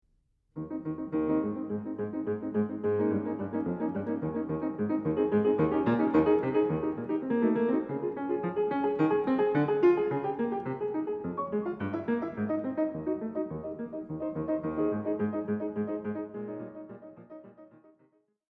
The second is like one of those passages that seems to evoke laughing, the lower part, the bouncing left hand, could easily be sung as Ha Ha Ha: